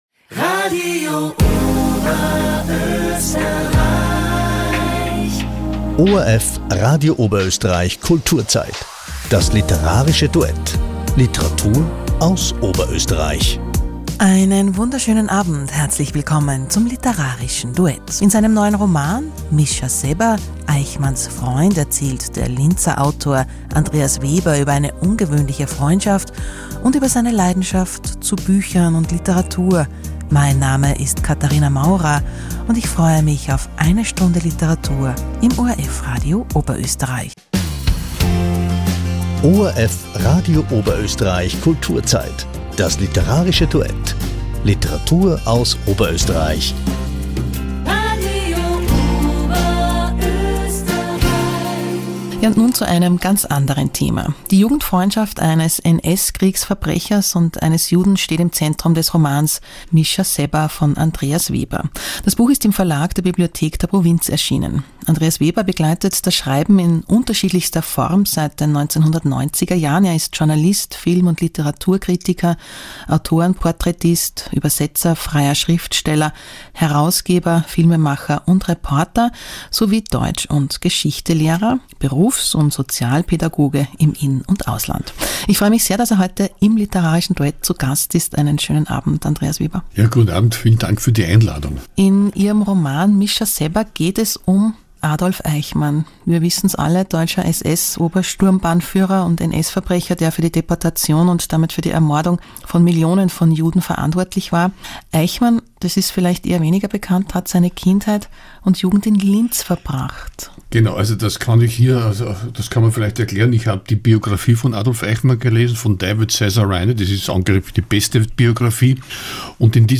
Mitschnitt_Radio_OOE_Eichmanns_Freund.mp3